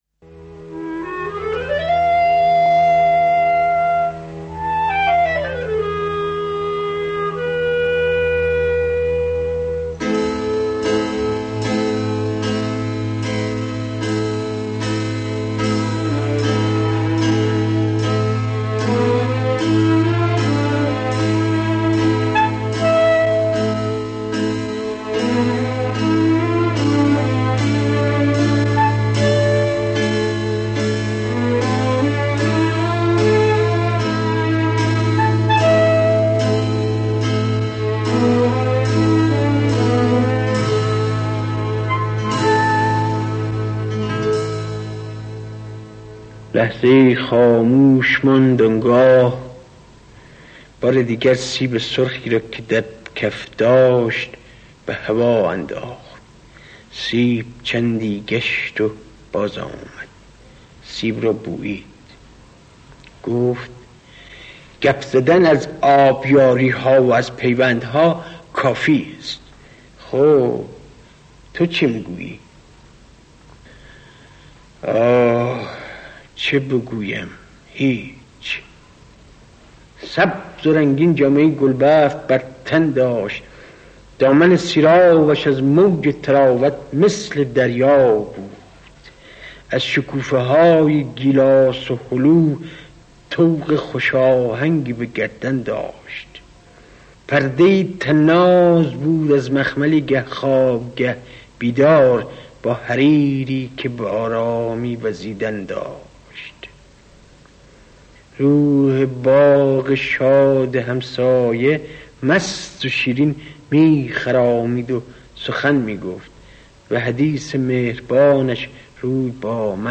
دکلمۀ شعر با صدای شاعر
آهنگساز: فریدون شهبازیان
دانلود دکلمه شعر پیوندها و باغ، با صدای مهدی اخوان ثالث